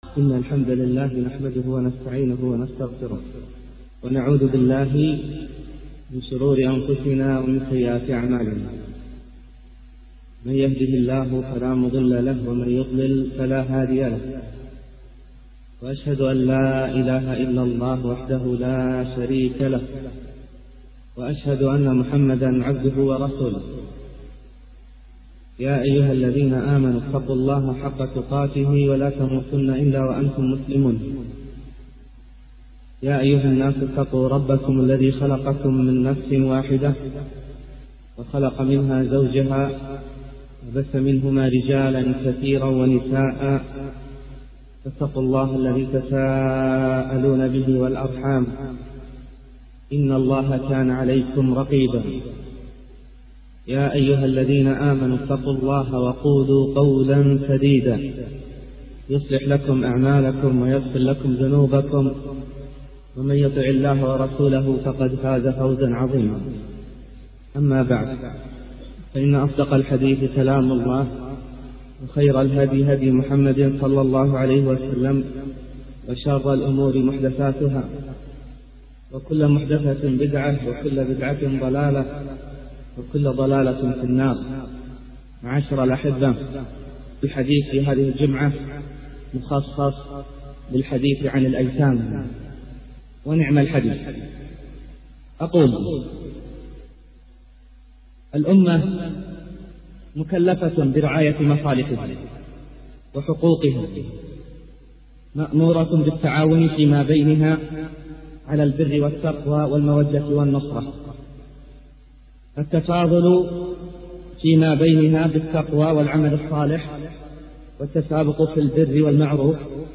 المحاضرات الصوتية